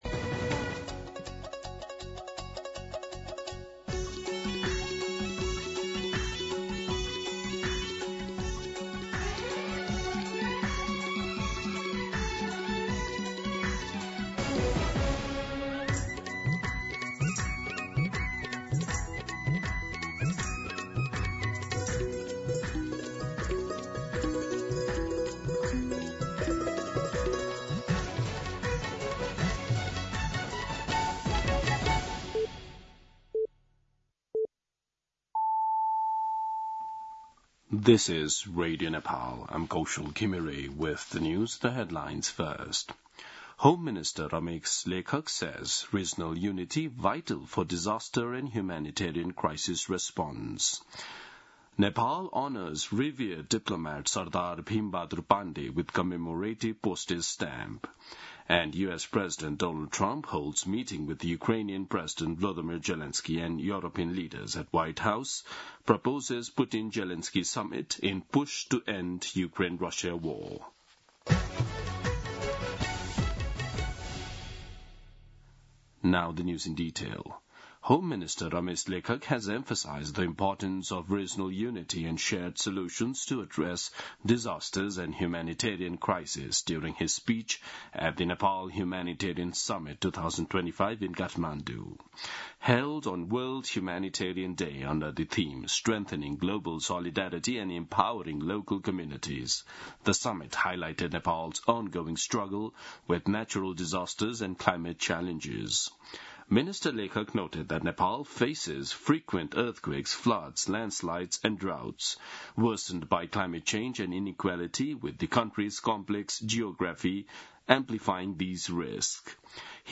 दिउँसो २ बजेको अङ्ग्रेजी समाचार : ३ भदौ , २०८२
2pm-English-News-03.mp3